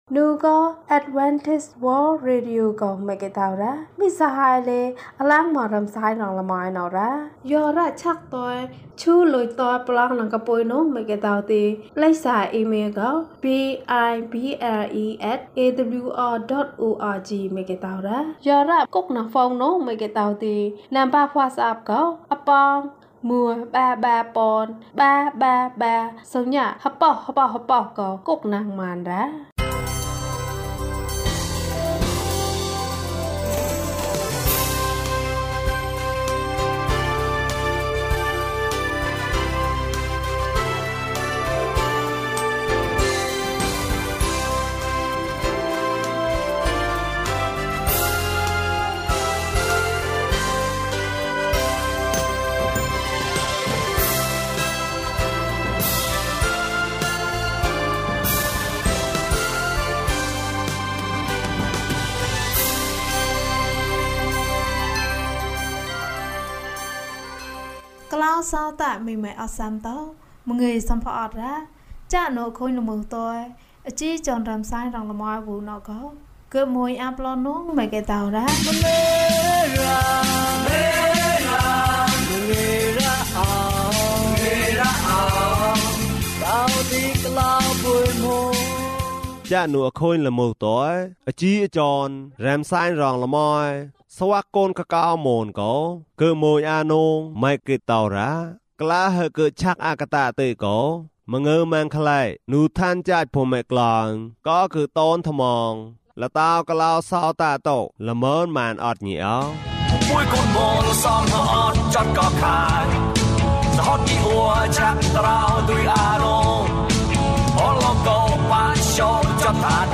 ခရစ်တော်ထံသို့ ခြေလှမ်း ၁၁။ ကျန်းမာခြင်းအကြောင်းအရာ။ ဓမ္မသီချင်း။ တရားဒေသနာ။